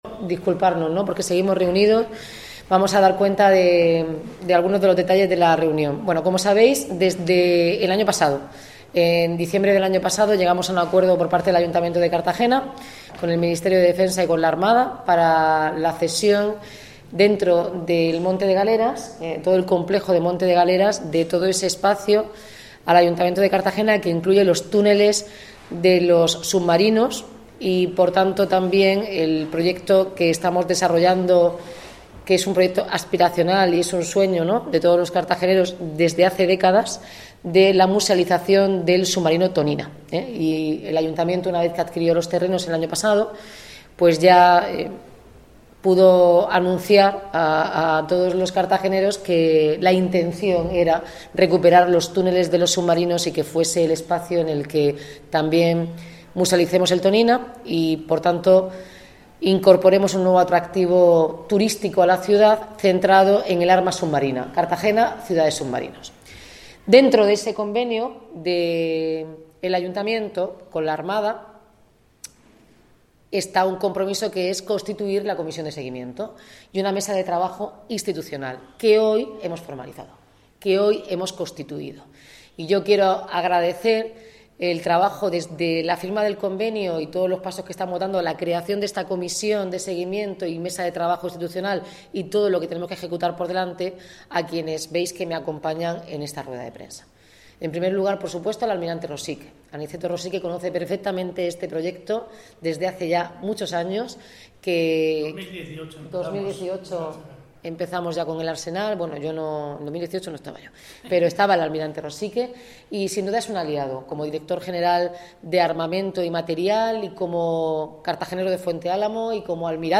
Declaraciones de Noelia Arroyo